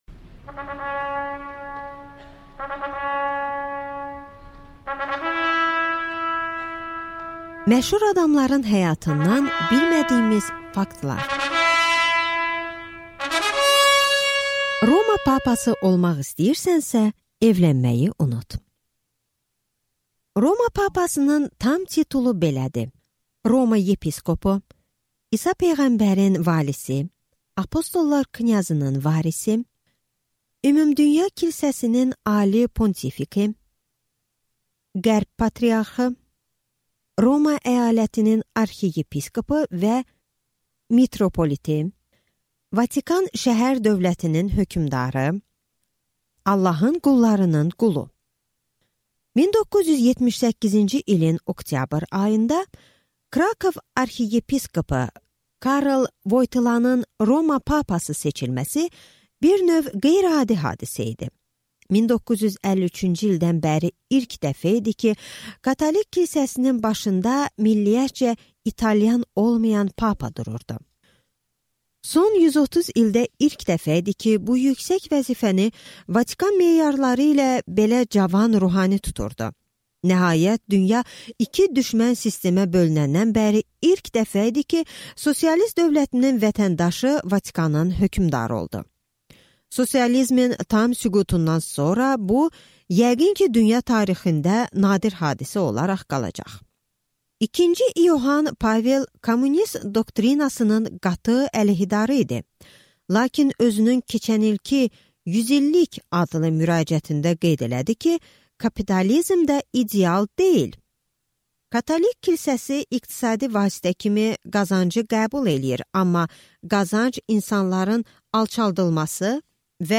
Аудиокнига Məşhur adamlardan faktlar | Библиотека аудиокниг